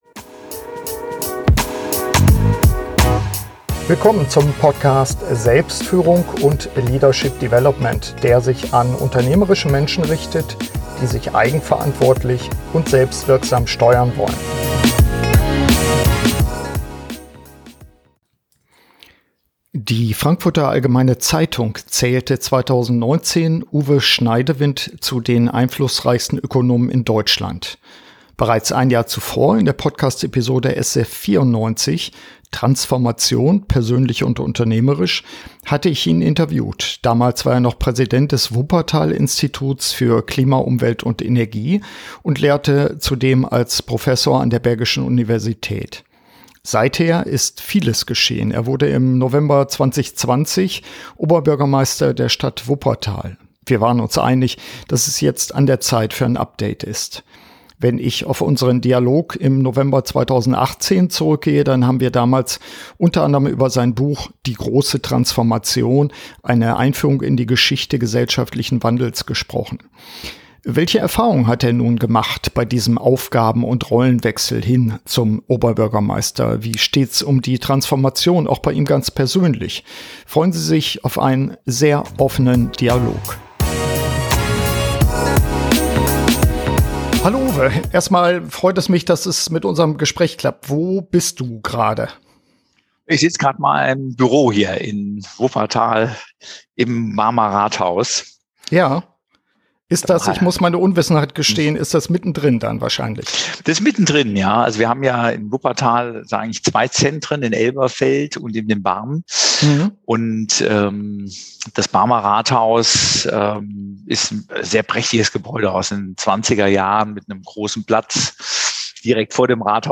SF175 Führungsaufgaben im Wandel - Update-Interview mit Uwe Schneidewind ~ Selbstführung und Leadership Development Podcast